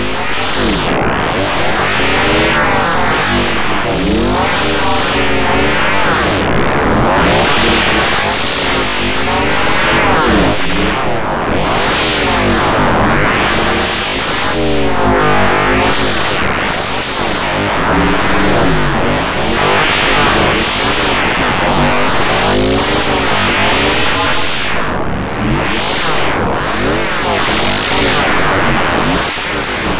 gurgly and synthetic, using PM and FM with both periodic and randomized oscillators):